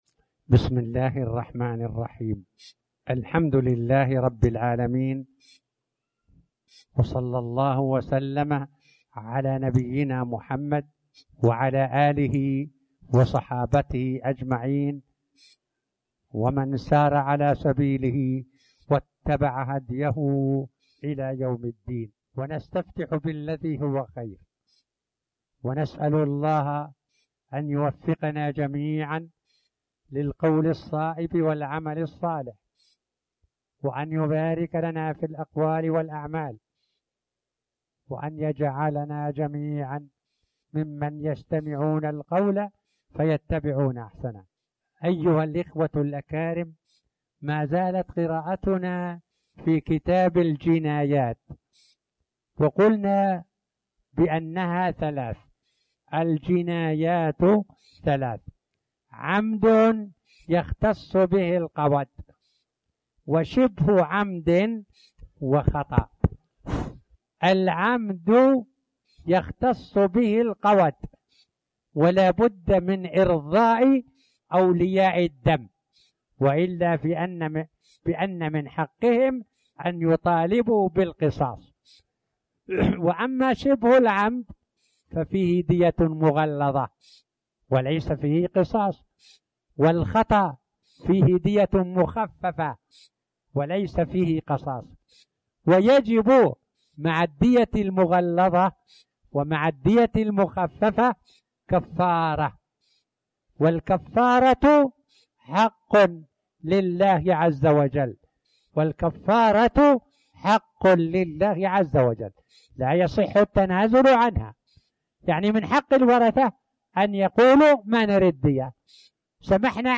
تاريخ النشر ١٧ شوال ١٤٣٩ هـ المكان: المسجد الحرام الشيخ